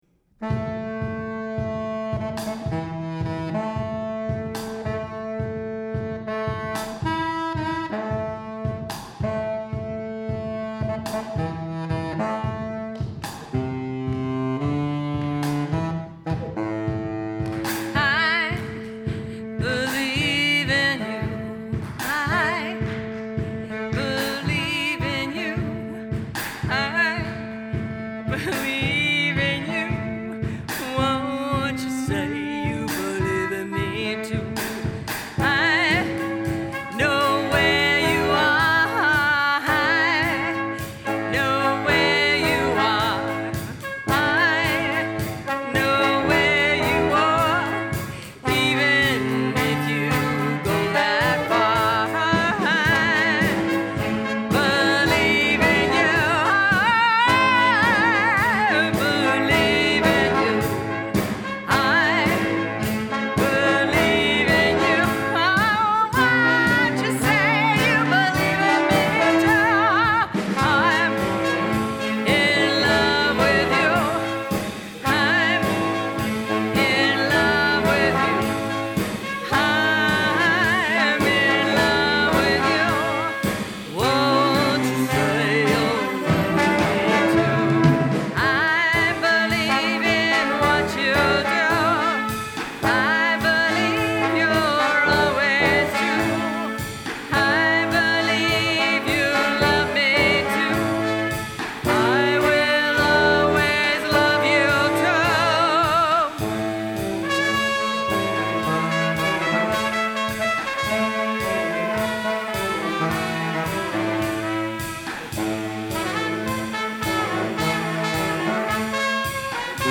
Full 3 song mini set from last week
We got three bootleg recordings the other week.
We have a new recording from last night’s rehearsal! Our first real original composition.